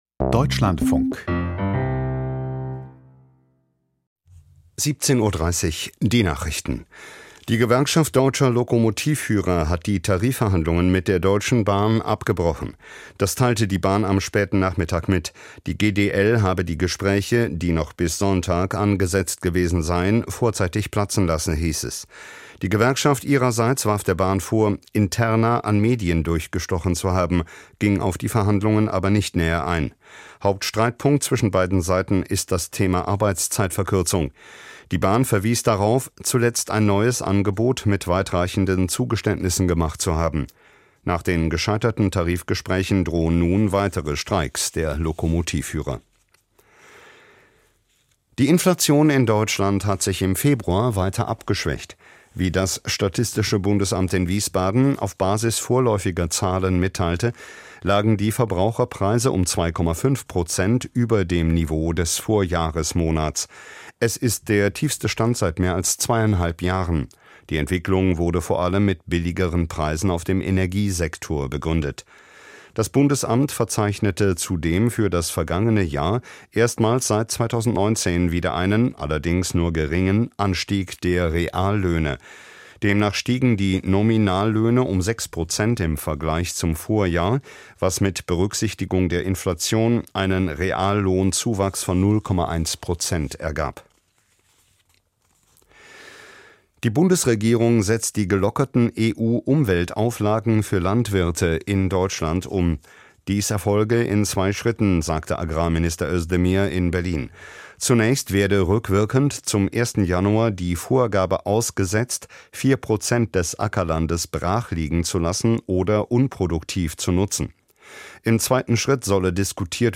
Studie beziffert wirtschaftliche Schäden des Klimawandels: Interview